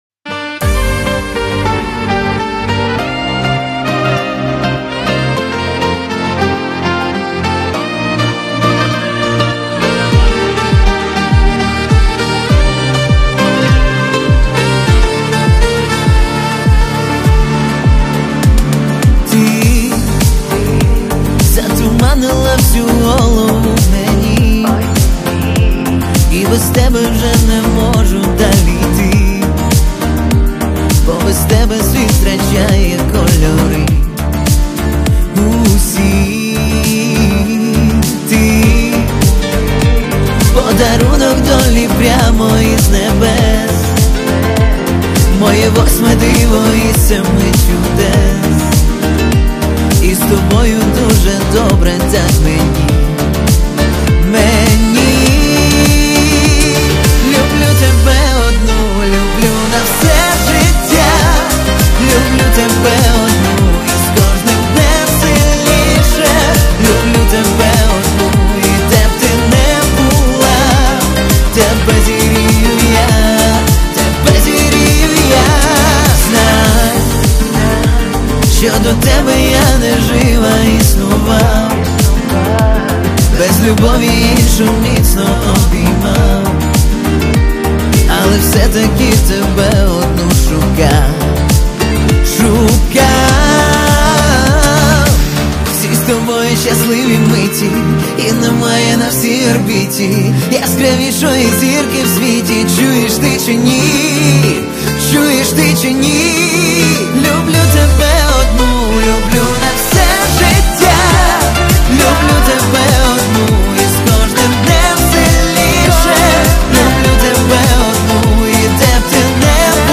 • Жанр:Поп